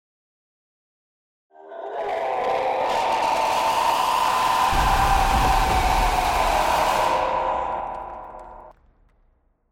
Sound Effects
Distant Demonic Scream And Debris